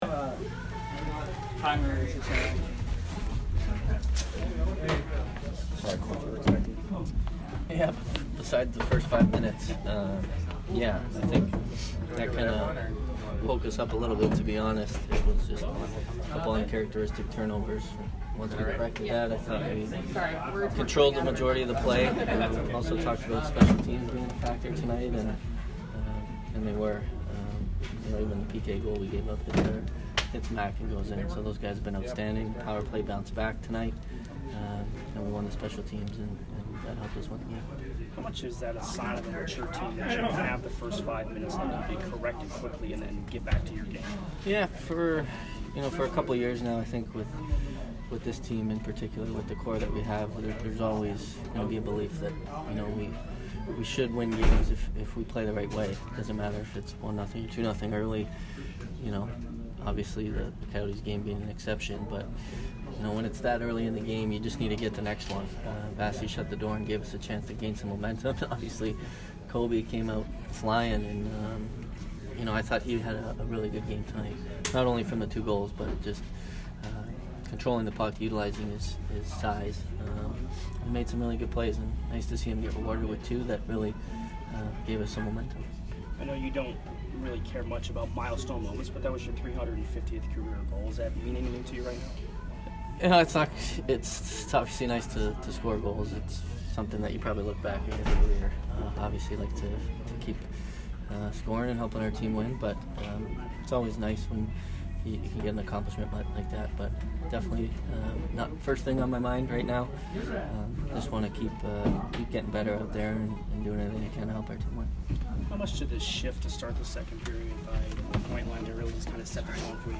Steven Stamkos post game 10/30